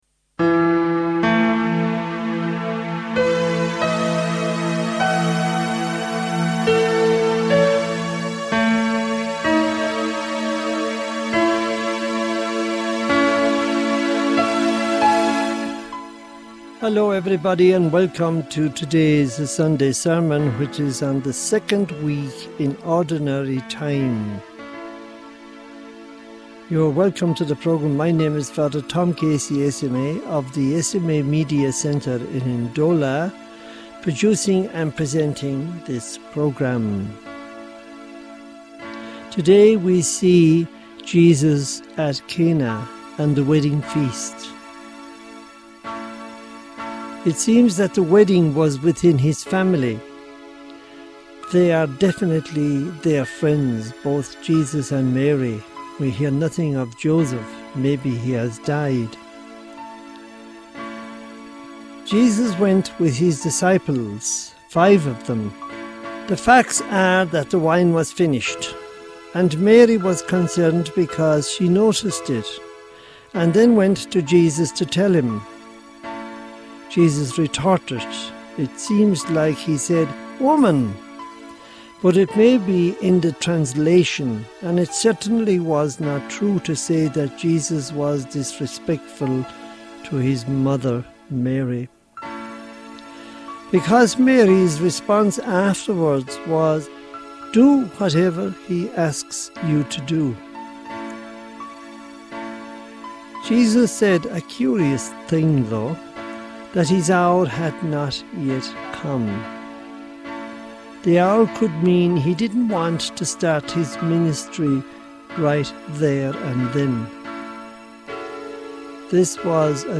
Homily for the 2nd Sunday of Ordinary Time, 2025